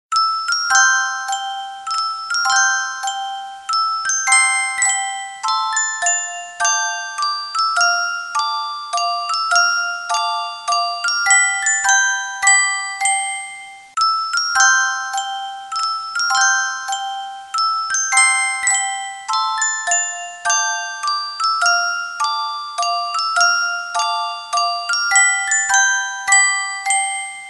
Music Box ringtone download